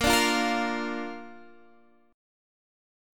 Bb Chord
Listen to Bb strummed